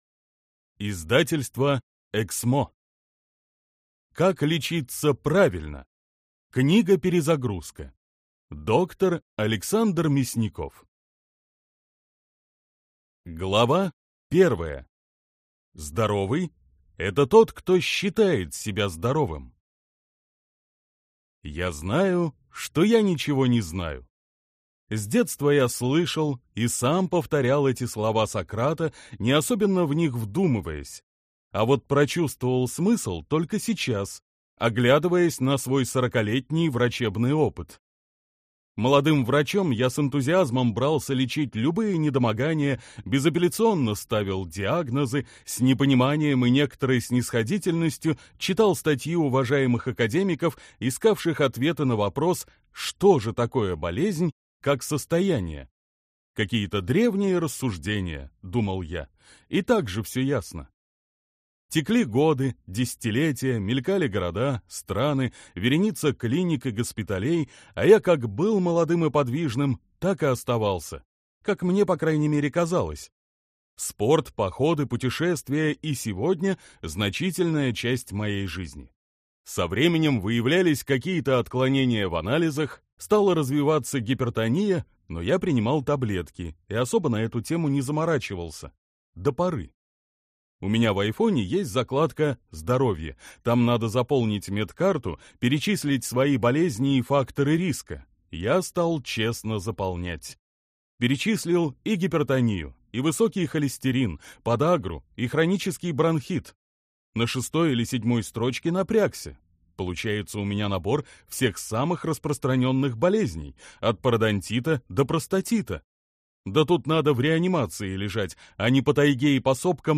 Аудиокнига Как лечиться правильно. Книга-перезагрузка | Библиотека аудиокниг